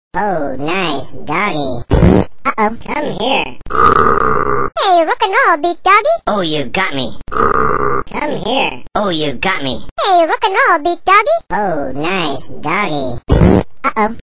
The random phrases produced are "Oh, nice doggie!", "Grrrrrr!", "Oh, you got me!", "Rock N� Roll big doggie!", "Come here!" and "Ouch! Don�t do that!". As an added bonus it also produces an occasional fart!